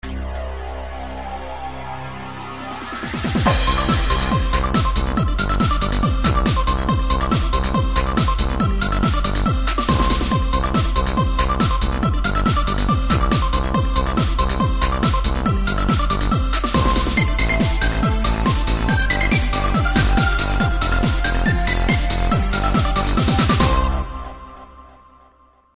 Techno [instrumental]